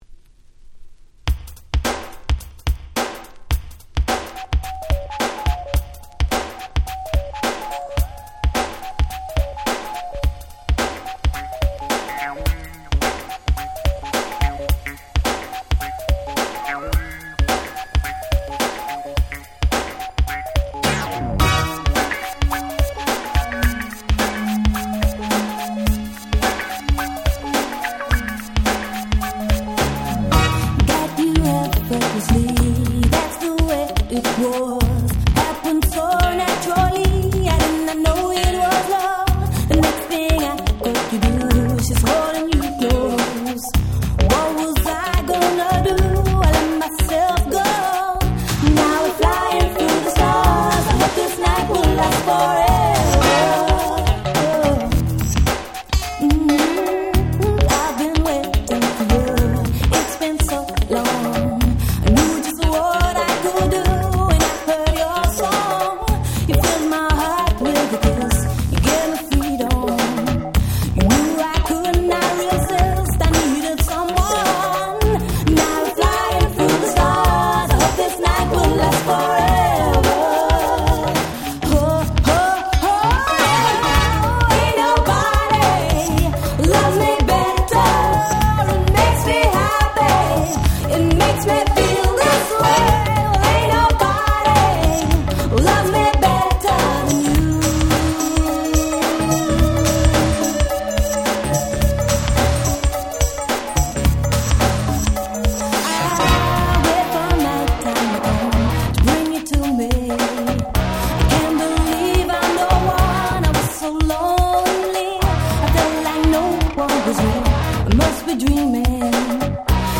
88' Nice Cover R&B !!
New Jack Swing感もあってなかなか素敵なカバーです！
80's Disco ディスコ NJS ハネ系 ニュージャックスウィング